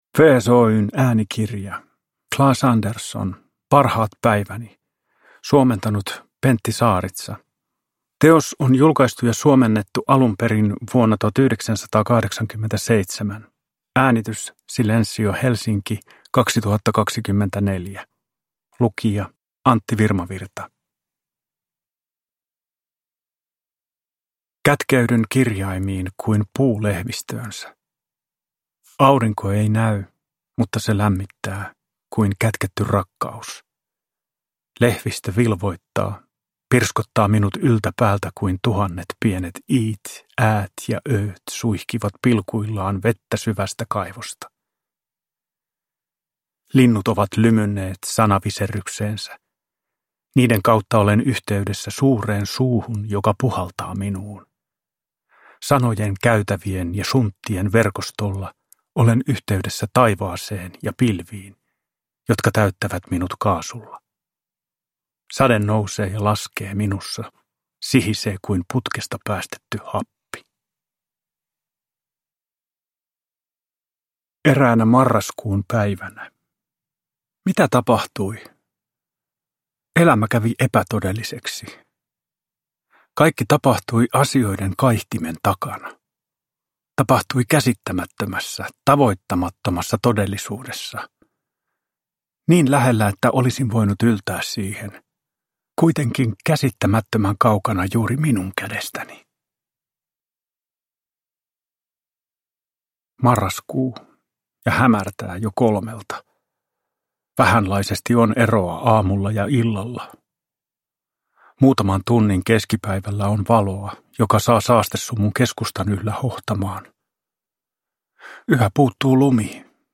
Parhaat päiväni – Ljudbok